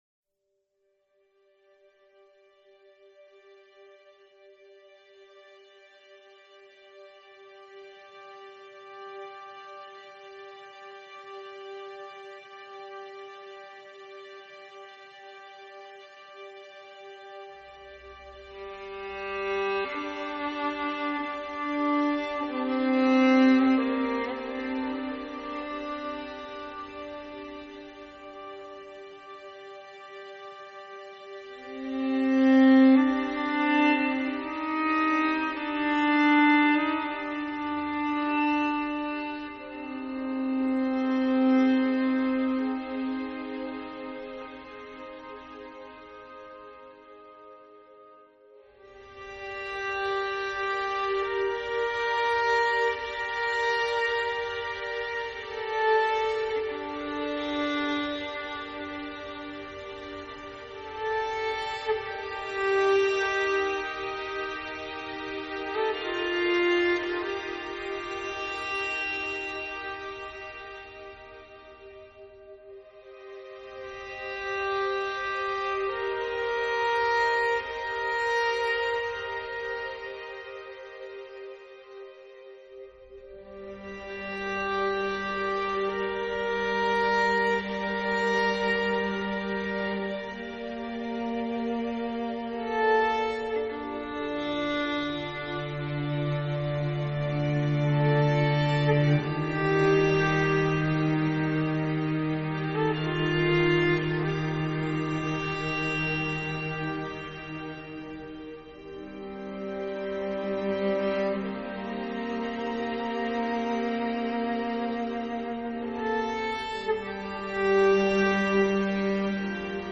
اجرای ارکستر فیلارمونیک پراگ
Soundtrack, Orchestral